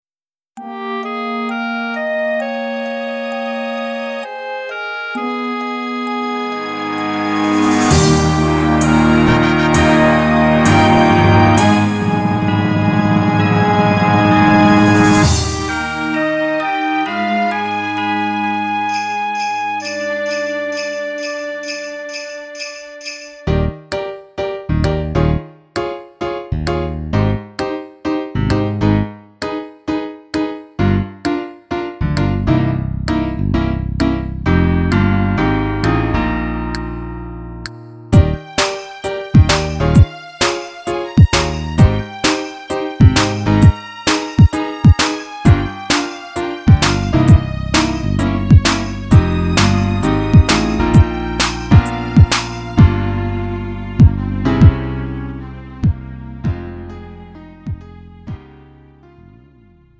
음정 -1키 4:01
장르 가요 구분 Lite MR
Lite MR은 저렴한 가격에 간단한 연습이나 취미용으로 활용할 수 있는 가벼운 반주입니다.